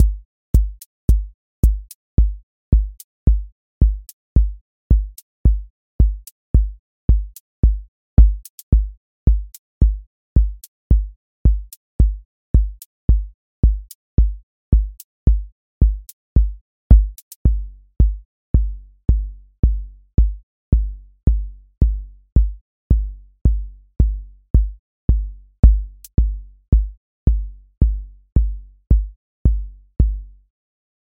four on floor QA Listening Test house Template